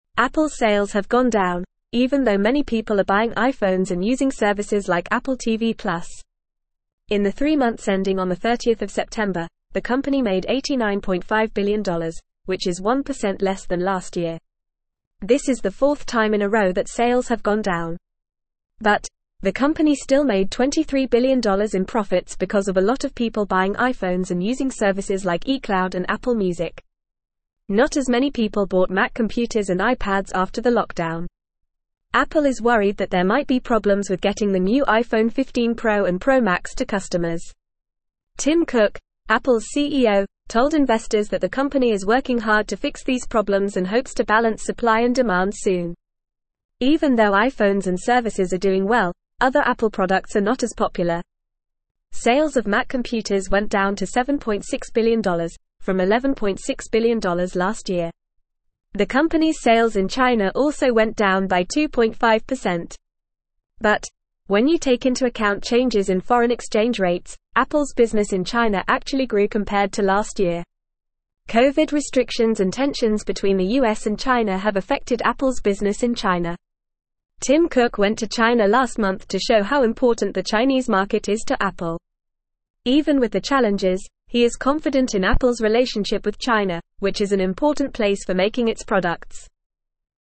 Fast
English-Newsroom-Upper-Intermediate-FAST-Reading-Apples-Sales-Decline-Despite-Strong-iPhone-Demand.mp3